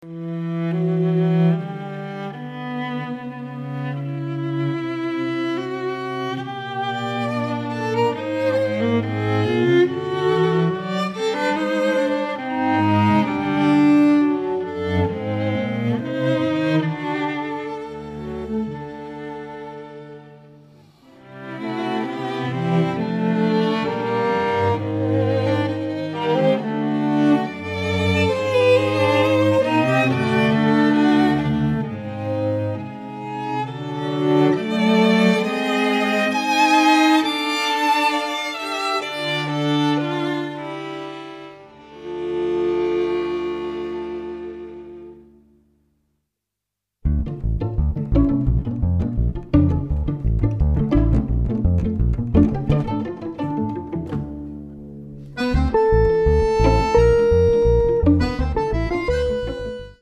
Accordion
chitarra elettrica
basso elettrico ed acustico
drums and percussion
violin
Violino, voce
viola
violoncello Cello